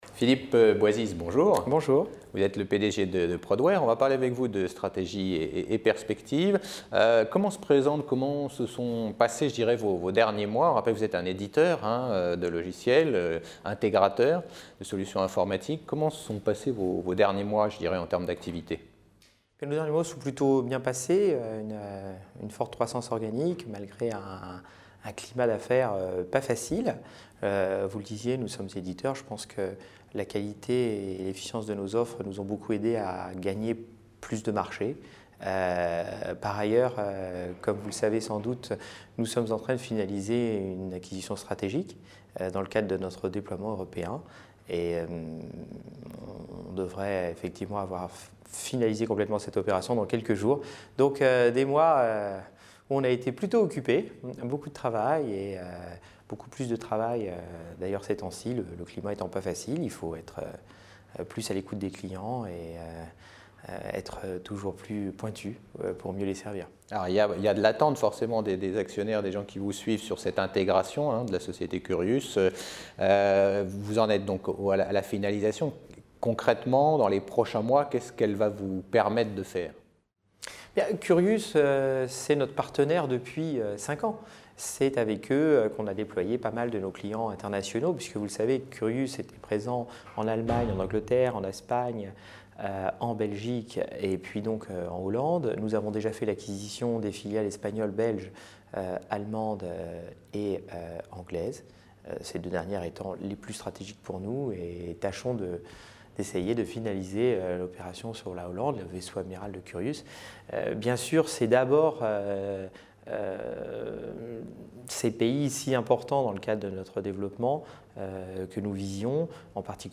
Réunion Alternext Gilbert Dupont : Stratégie et perspectives de l'éditeur de logiciels